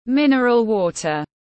Nước khoáng tiếng anh gọi là mineral water, phiên âm tiếng anh đọc là /ˈmɪn.ər.əl ˌwɔː.tər/
Mineral water /ˈmɪn.ər.əl ˌwɔː.tər/
Mineral-water-.mp3